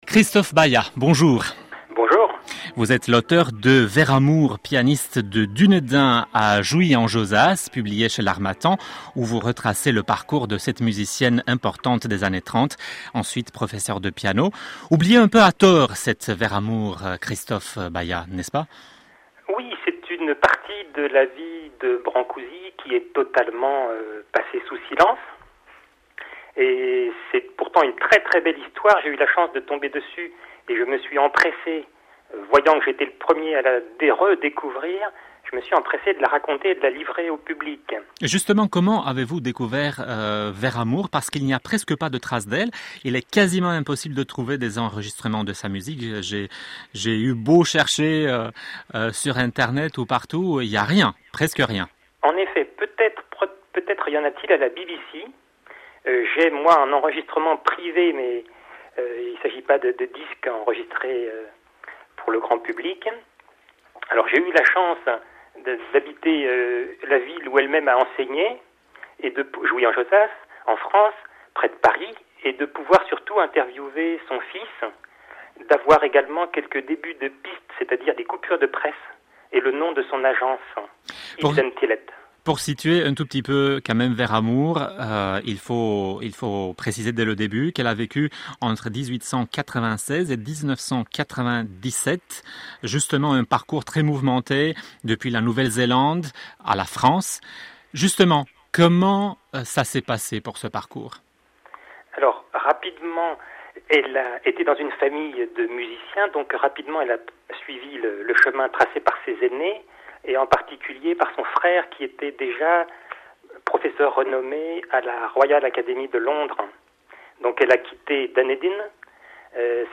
Émission Radio
interview sur Radio Roumanie